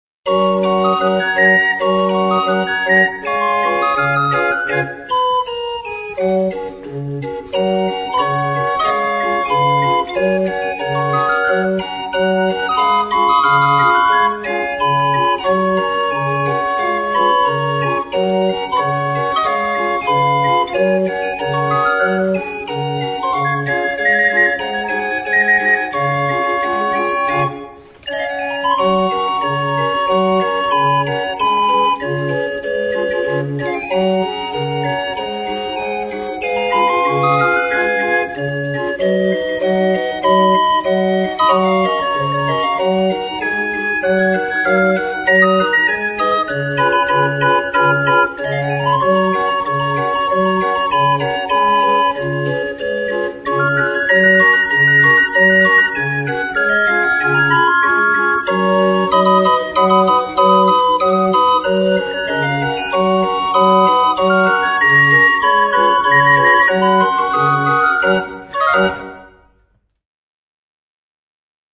DREHORGELSPIELER
Die Drehorgeln sind keine elektronischen Imitate, sondern gepflegte Originalinstrumente aus der Zeit um 1900.
Alle Hörproben sind original Aufnahmen mit den Drehorgeln !
drehorgel_im weissen r.mp3